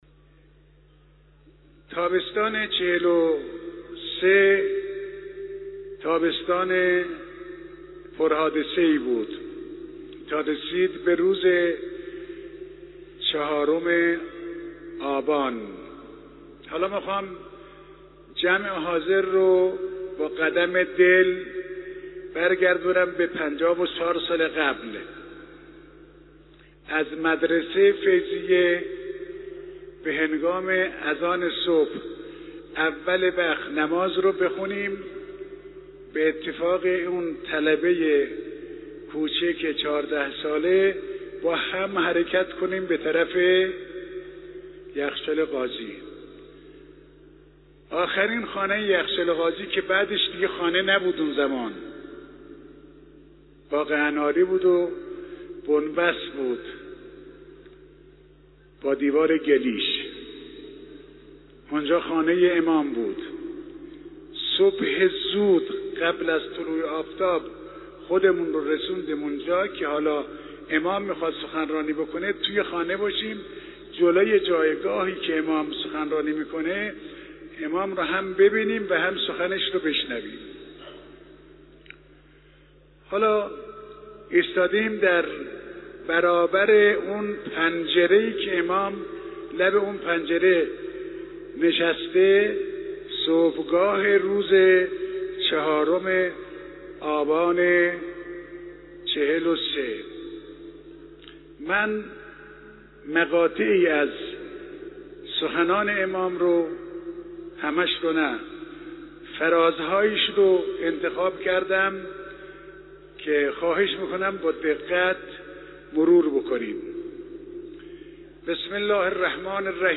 به گزارش خبرنگار سیاسی خبرگزاری رسا، حجت الاسلام والمسلمین محمدحسن رحیمیان تولیت مسجد مقدس جمکران امروز در سخنرانی پیش از خطبه های نماز جمعه قم که در مصلای قدس برگزار شد، با اشاره به سخنرانی امام راحل در چهارم آبان 1343 در مخالفت با کاپیتولاسیون گفت: در حال حاضر کسانی که از سال 41 در جریان نهضت بودند اندک هستند.